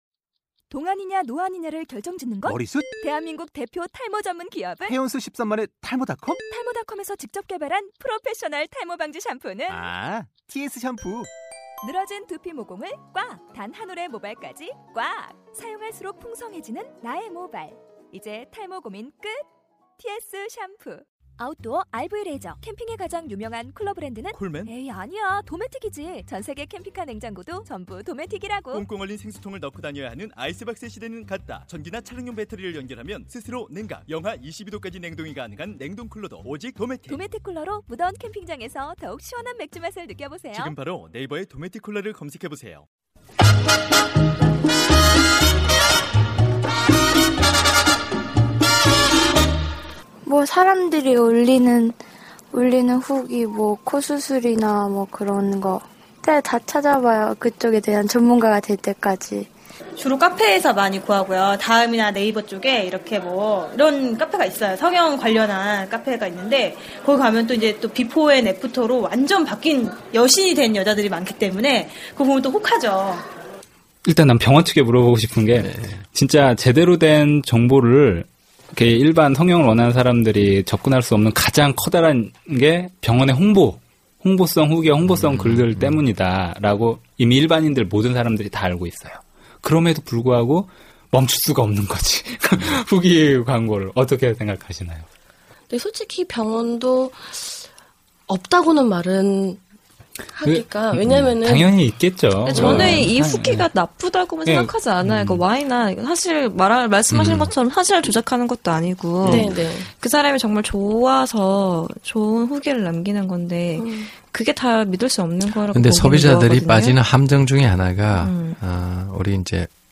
파일럿 방송 성형외과 전문의, 성형외과 관계자, 그리고 일반인이 들려주는 성형수술에 관한 진솔한 이야기 인구대비 성형수술인구 세계 1위를 기록하고 있는 대한민국 대한민국의 성형을 생각하는 모든 이들에게 꼭 필요하고 유용한 정보, 때로는 불편하고 껄끄러운 정...